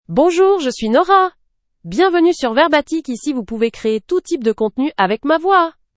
NoraFemale French AI voice
Nora is a female AI voice for French (France).
Voice sample
Female
Nora delivers clear pronunciation with authentic France French intonation, making your content sound professionally produced.